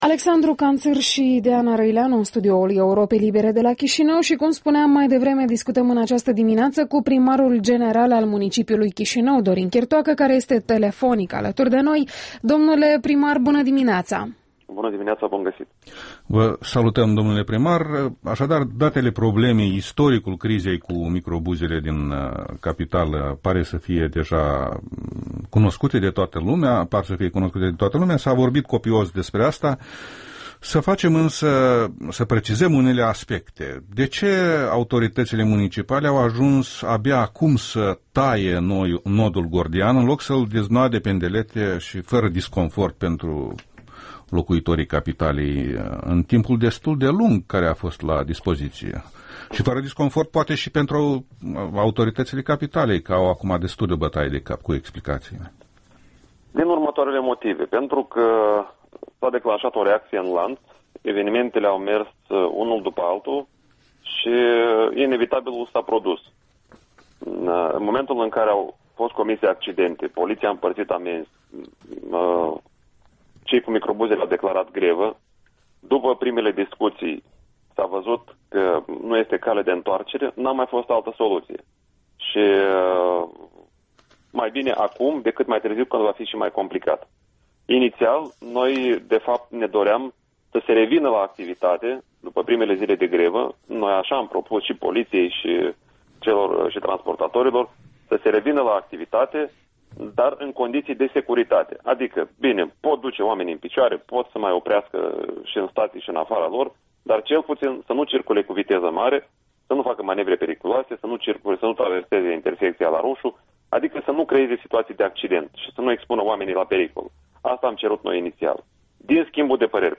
Interviul dimineții: cu primarul Dorin Chitoacă despre transportul în comun